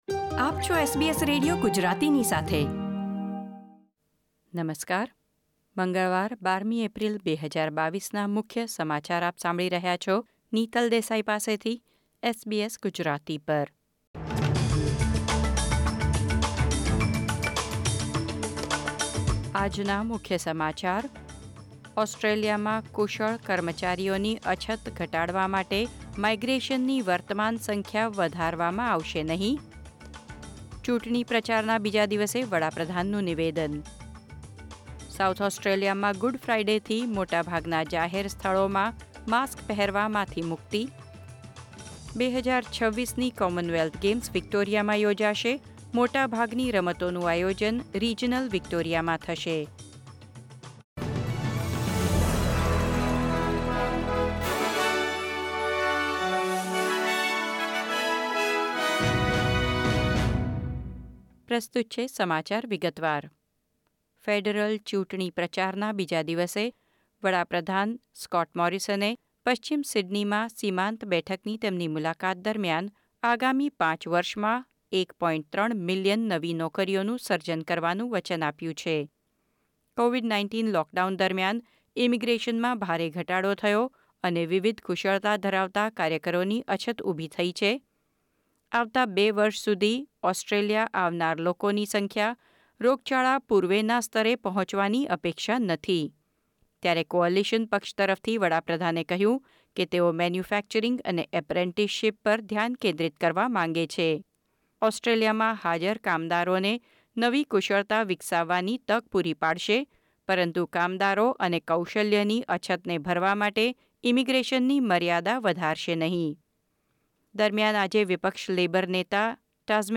SBS Gujarati News Bulletin 12 April 2022